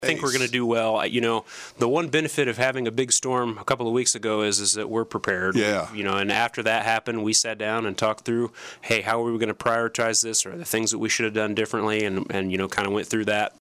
Wood joined in on the KSAL Morning News Extra with a look back at how Salina dug out of the last storm — and how lessons learned will help guide crews with the coming snowfall that could leave behind another 4 to 8-inches of snow.